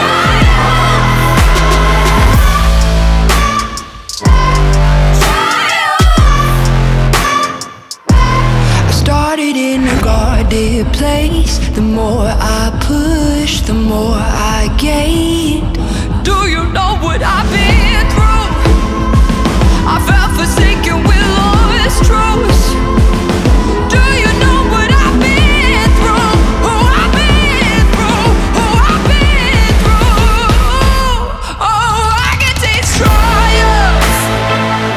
2024-01-08 Жанр: Альтернатива Длительность